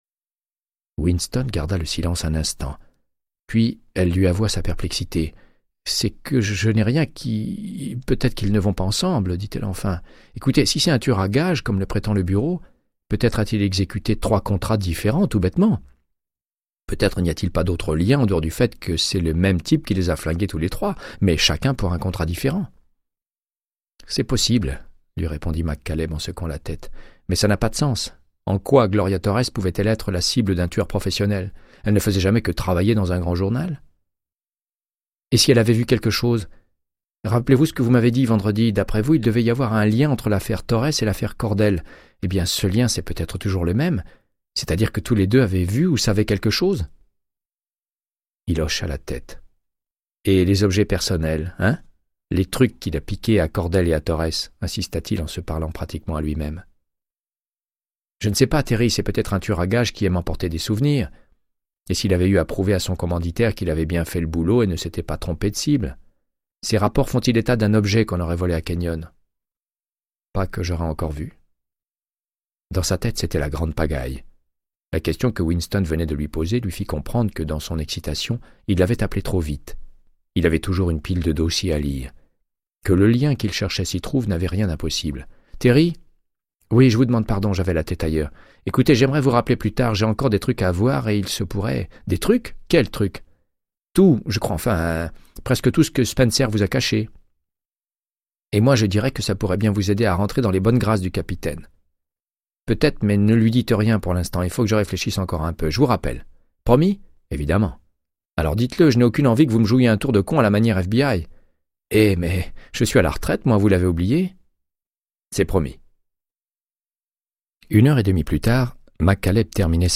Audiobook = Créance de sang, de Michael Connelly - 103